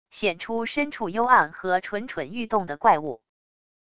显出深处幽暗和蠢蠢欲动的怪物wav音频